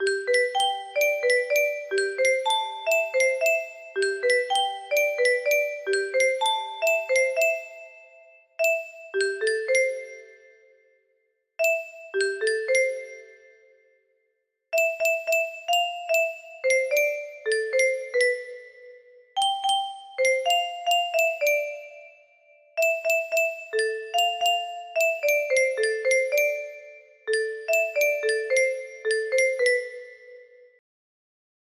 For Ian music box melody